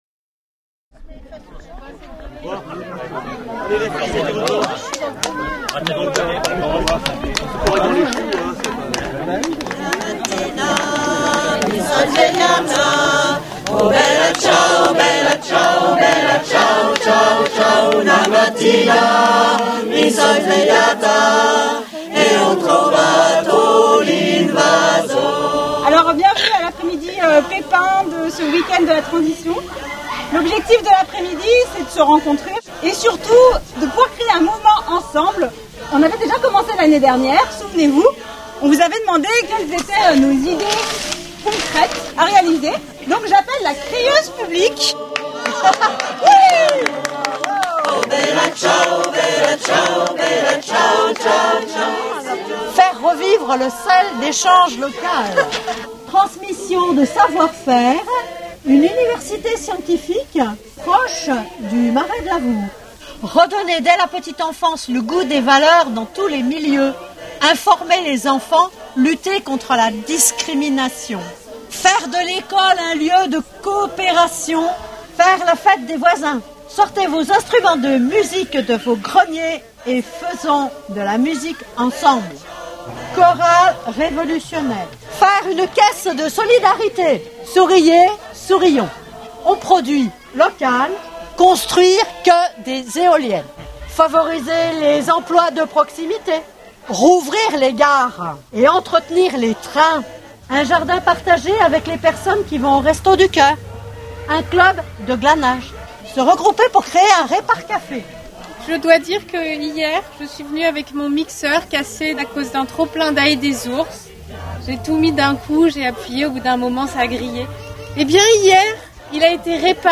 Etions-nous ensemble le dimanche 2 octobre au J’art d’Ain partagé, dans le cadre de Bugey en transition, lors de la